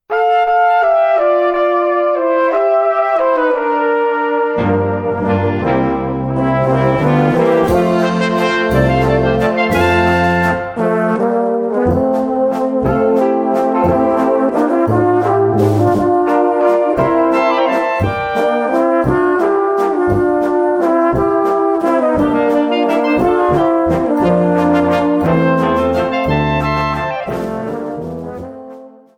Gattung: Walzer mit Gesang
Besetzung: Blasorchester